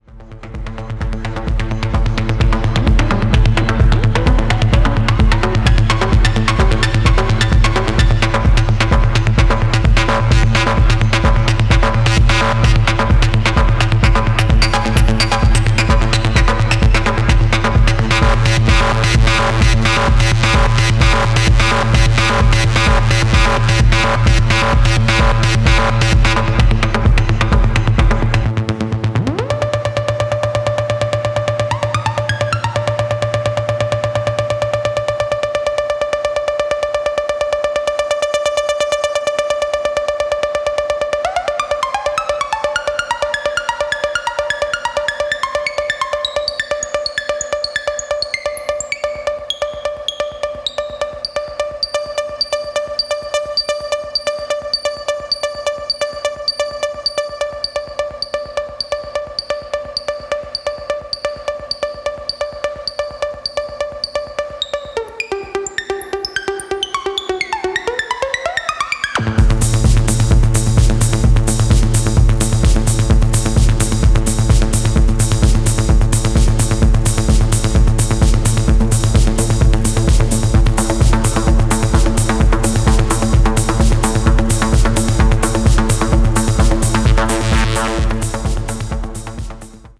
EBM系のストーンドなグルーヴと切れ味抜群のシンセ・テクスチャーで迫る
ダークな地下テック・ハウス/テクノ跨いで暴発必至のキラー曲に仕上がっています。